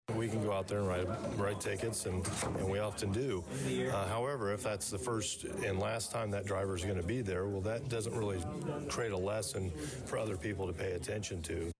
Danville Police Chief Christopher Yates (back row, middle) speaks during Danville City Council meeting.